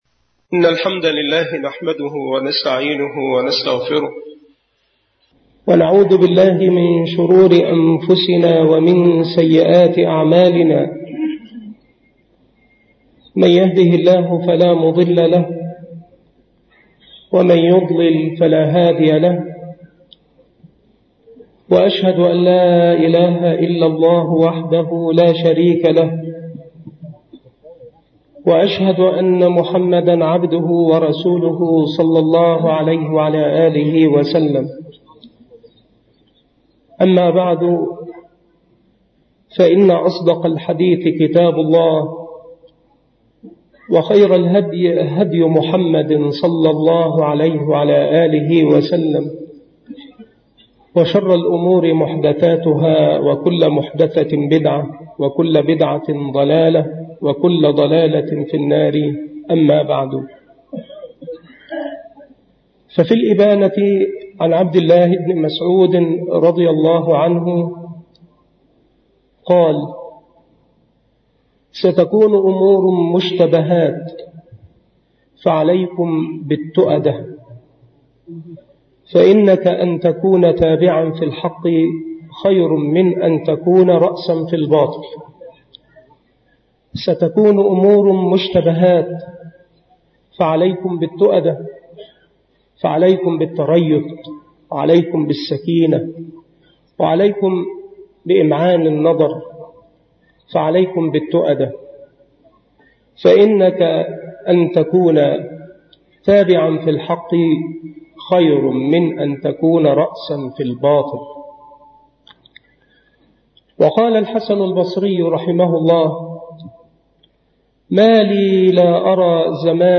مكان إلقاء هذه المحاضرة بمسجد أولاد غانم - منوف - محافظة المنوفية